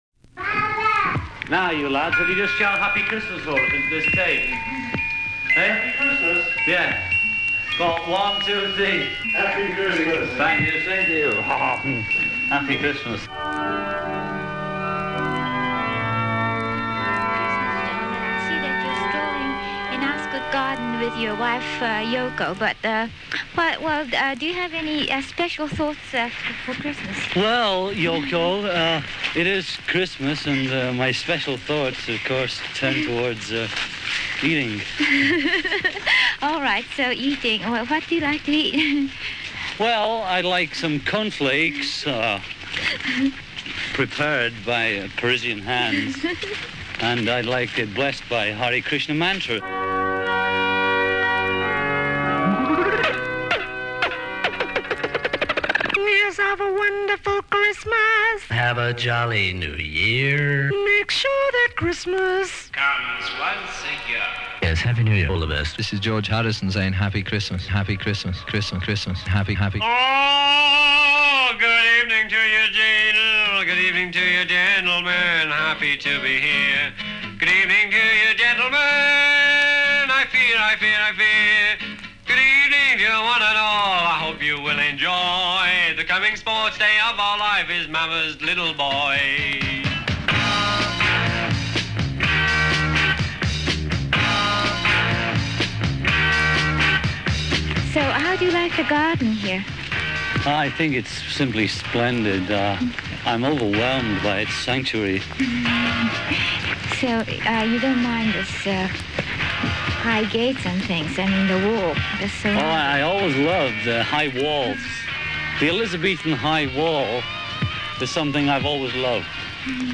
The previous year, the Christmas message changed from scripted messages talking directly to the fans, to sketch comedy, mostly Paul's idea, but enthusiastically joined in by the other three. 1967 brings a similar production, but as the members of the group start desiring to go their separate ways, this is also reflected in the Christmas records, as the final two years bring messages recorded in bits and pieces recorded separately by each Beatle and assembled together later.
Recorded in fall of 1969 at John and Yoko's home in Ascot, Ringo's home in Weybridge, Paul's home in London, and the London offices of Apple.